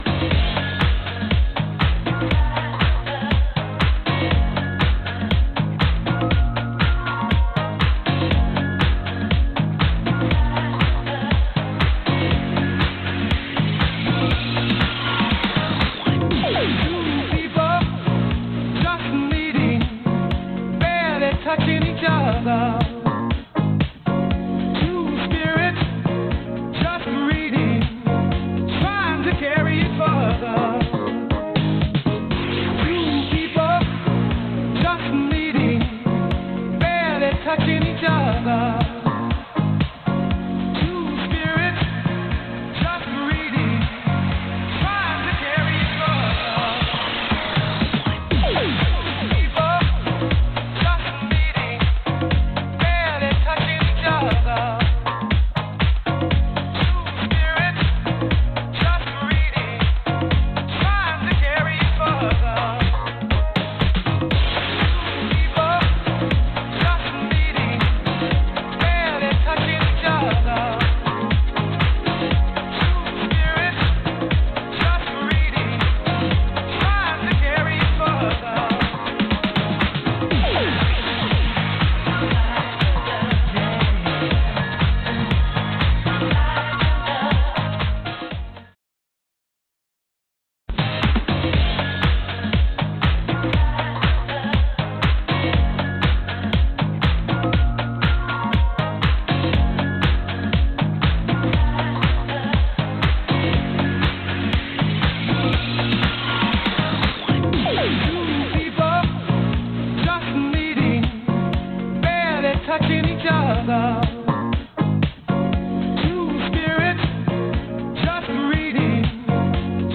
vocalist
a complete NU Disco rework
Nu Disco at it's best